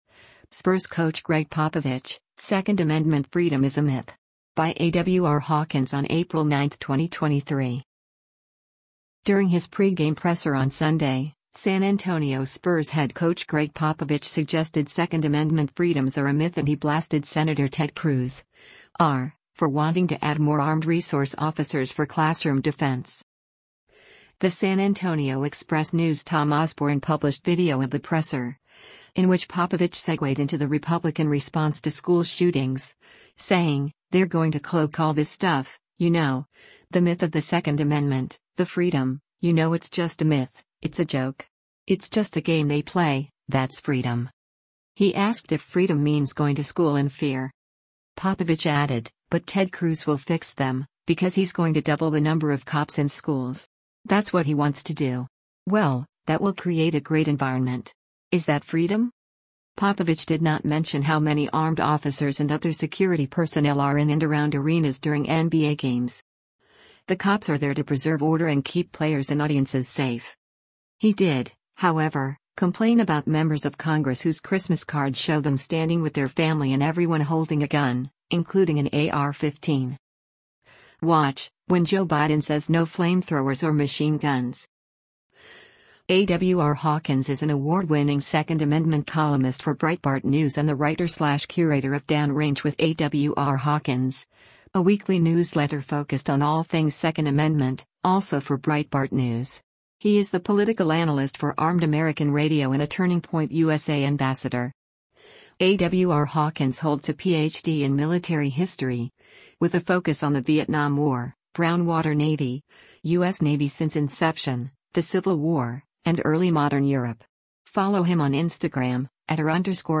During his pre-game presser on Sunday, San Antonio Spurs head coach Gregg Popovich suggested Second Amendment freedoms are “a myth” and he blasted Sen. Ted Cruz (R) for wanting to add more armed resource officers for classroom defense.